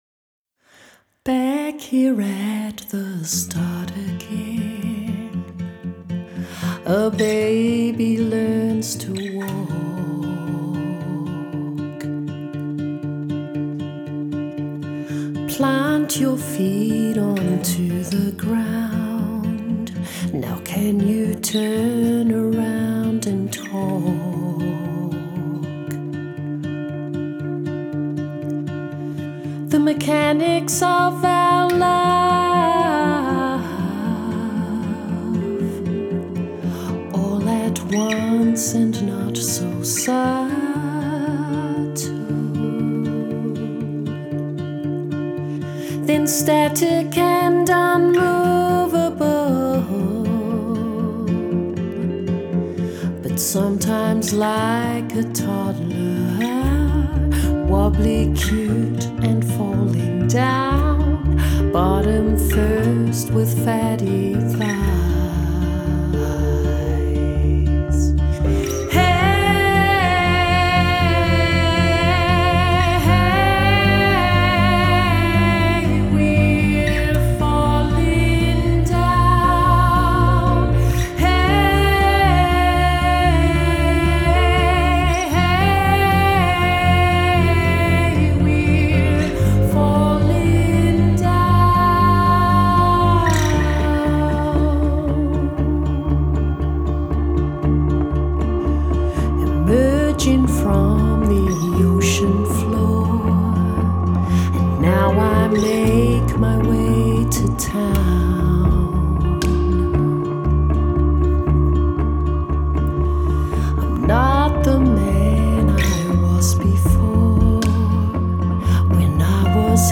small choir